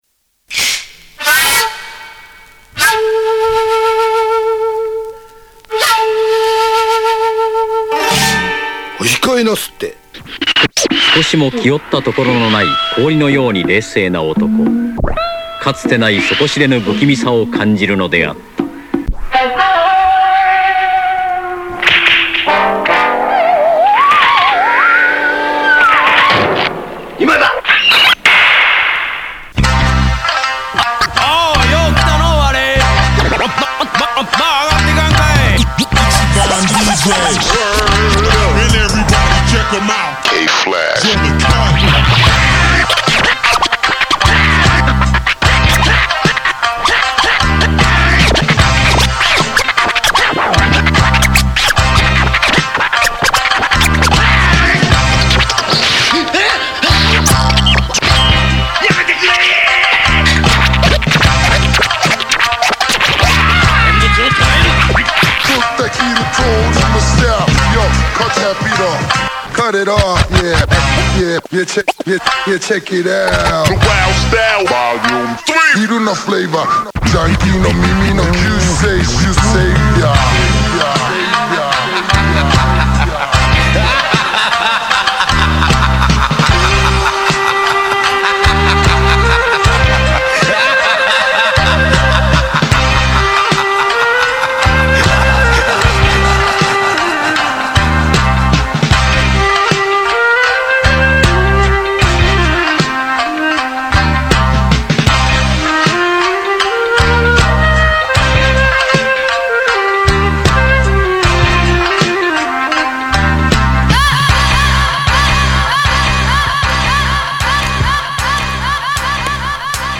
極上和モノクルーズ。